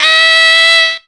LO WOBBLE.wav